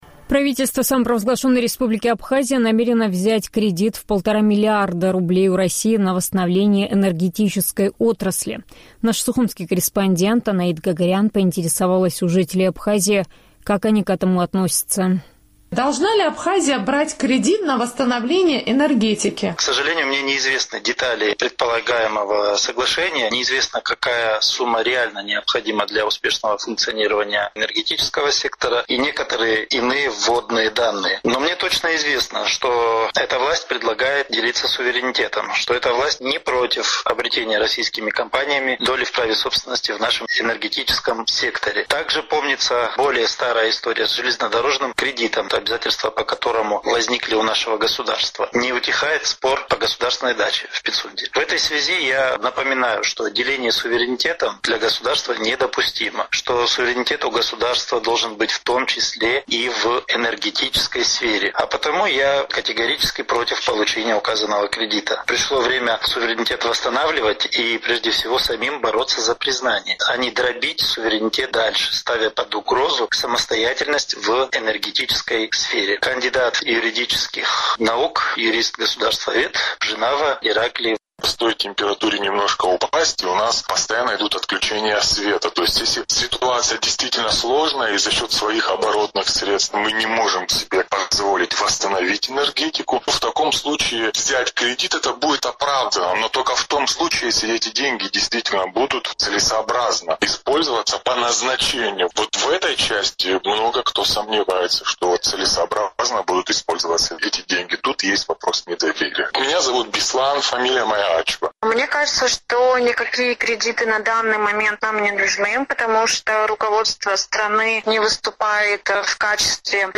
Сухумский опрос – о кредите на энергетику